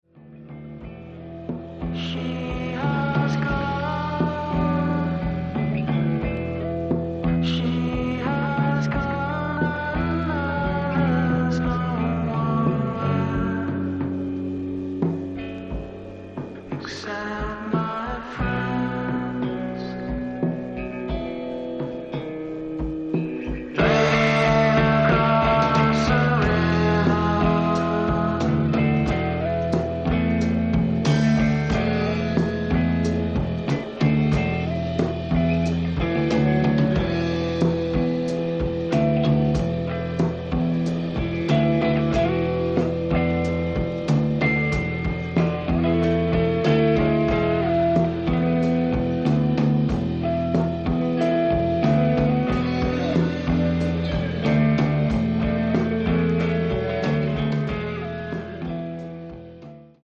Sweet ambient vocal loveryliness